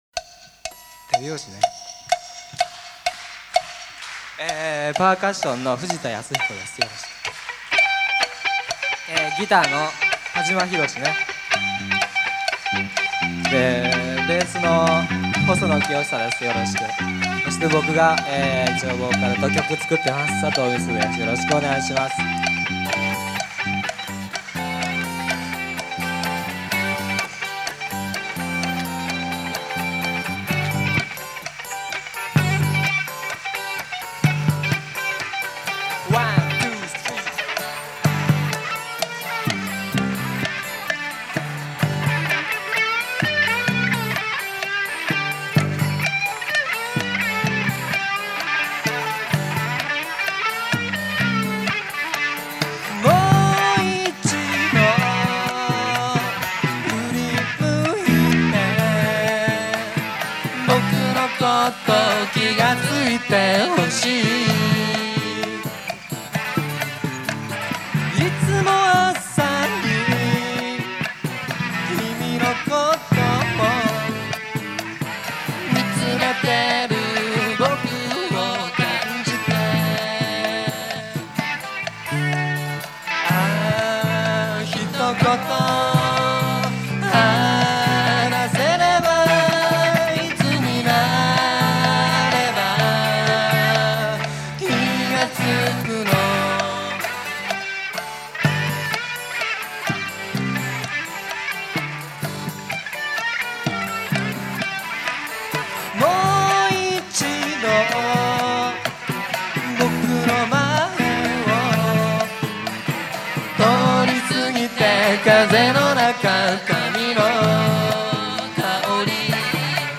場所：神奈川公会堂
イベント名：横浜ふぉーく村コンサート